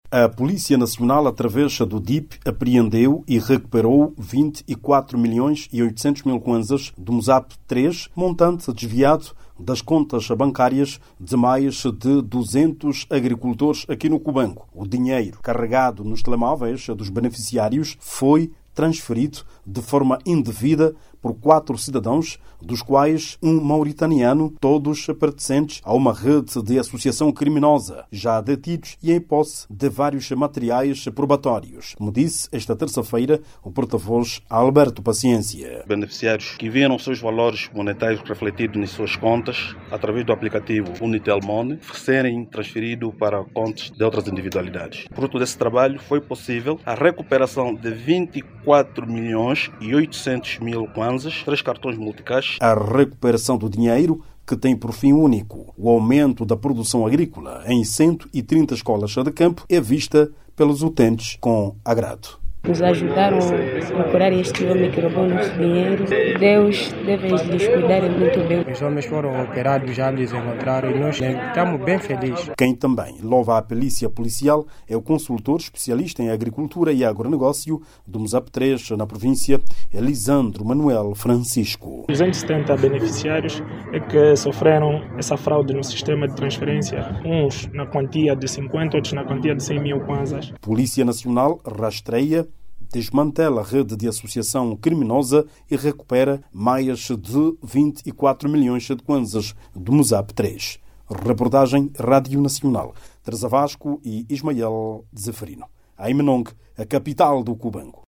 No âmbito das investigações, quatro cidadãos, entre eles um mauritaniano, foram detidos por envolvimento neste esquema fraudulento. Jornalista